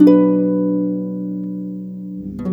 Rock-Pop 09 Harp 05.wav